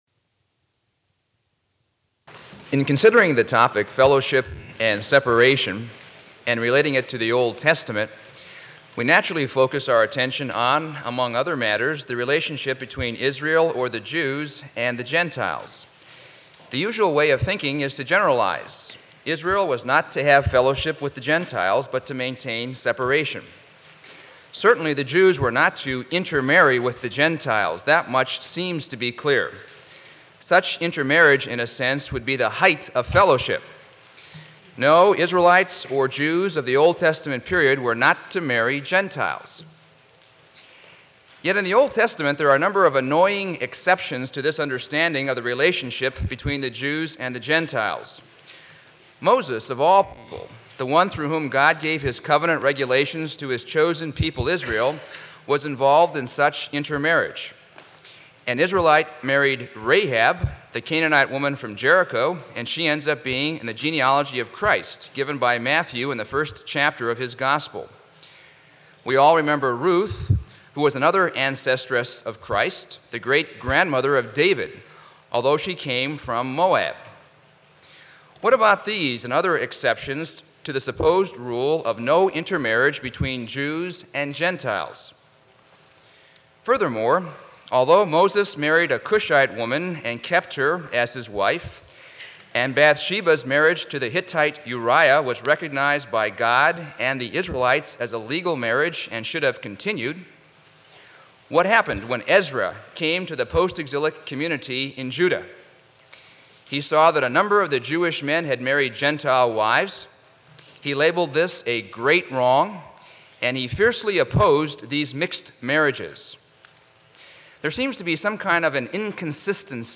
Symposia 1992